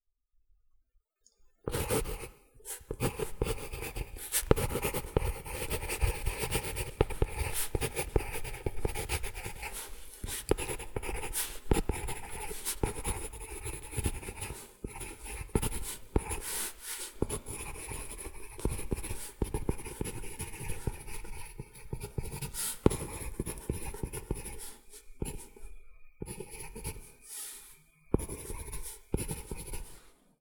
pencil-scratch-1.wav